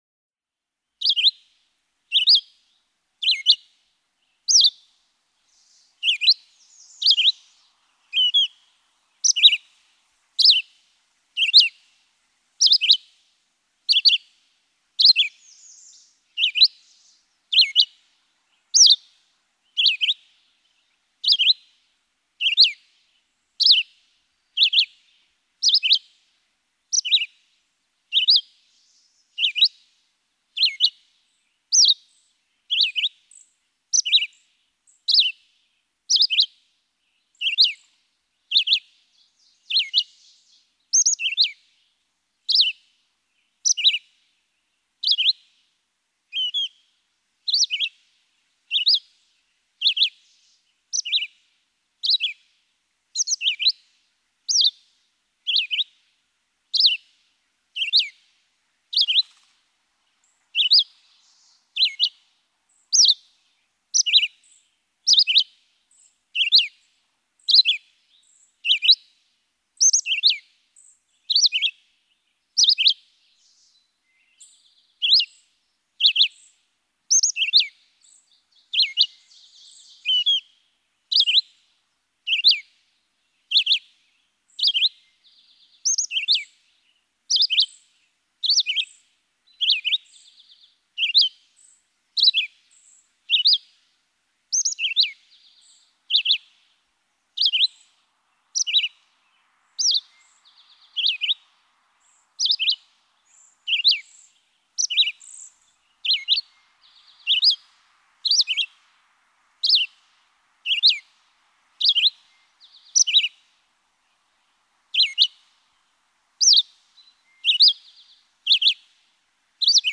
Red-eyed vireo
Adult song. This male also has some easily recognizable, unique songs—try listening for other renditions of the one at 0:08.
Savoy Mountain State Forest, Berkshire Mountains, Massachusetts.
164_Red-eyed_Vireo.mp3